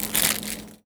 R - Foley 78.wav